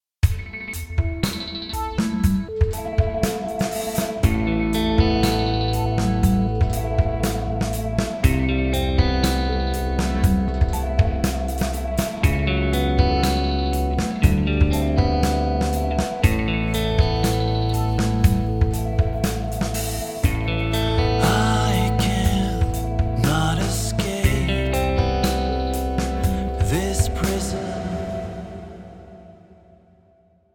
Emotional progressive rock!